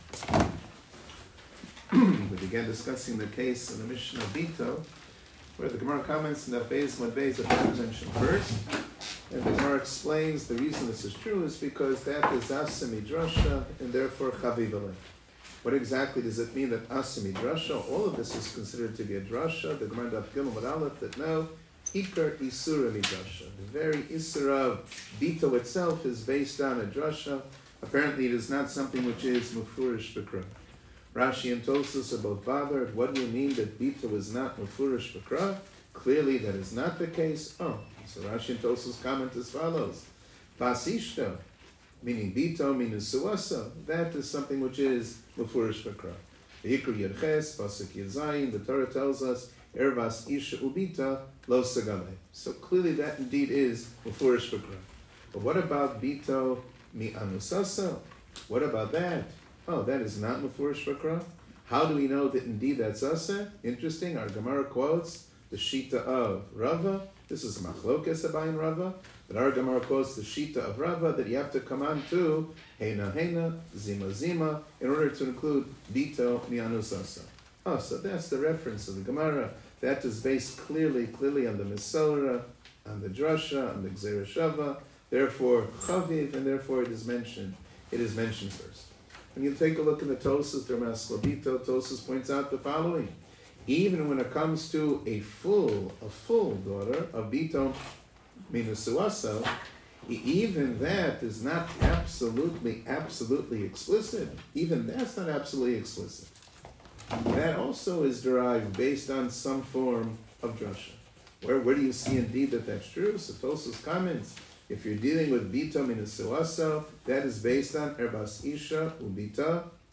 Shiur 10 - בתו חלק ב